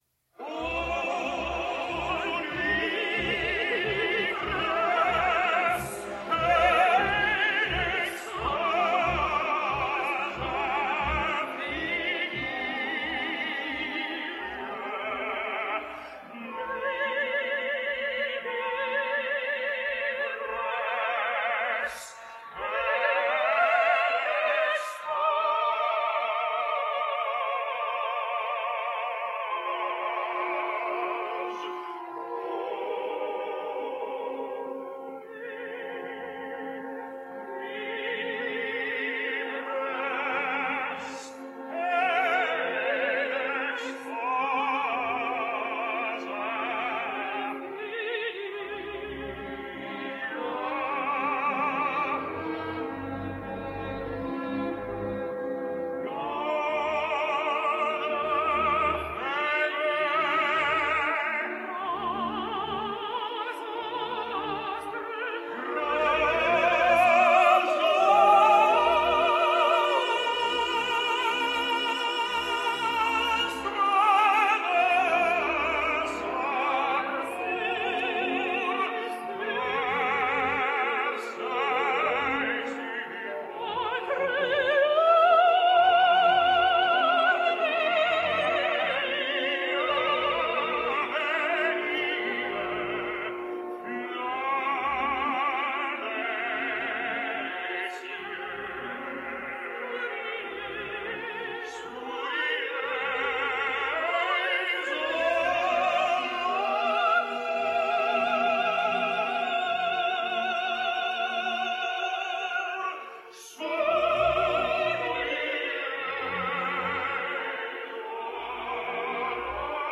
This was the role which really put Vickers on the map, and here he is partnered by the British mezzo Josephine Visy, as Dido, queen of Carthage, reveling in a Nuit de Veers, a Night of Rapture.
Jon Vickers and Josephine Visy drift off together into the Carthaginian night, part of the love duet from Berlioz, the Trojans.